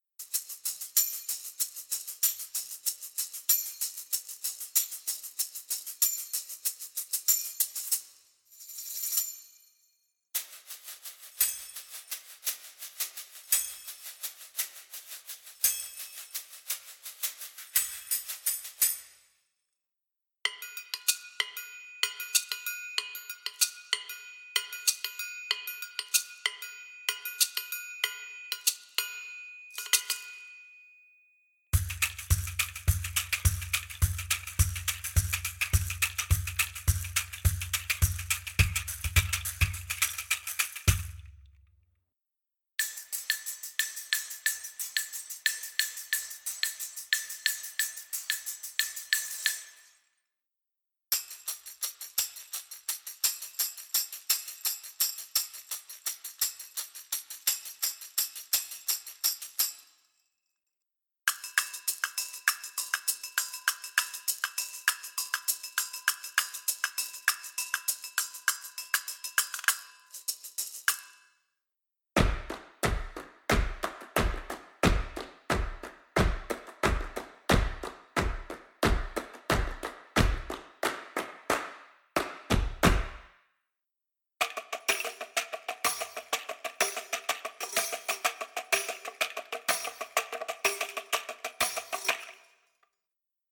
这个由手鼓、振动器和小型手打击乐组成的样本库可让您轻松创建和控制逼真的人类表演的声音。
相反，我们录制了打击乐表演，收集了向前和向后的推力、重音、扭曲和重要的“中间”声音的变化样本，这些声音将所有东西粘合在一起。
101 种新选项：手鼓、振动器、小手打击乐、拍手、按扣、跺脚等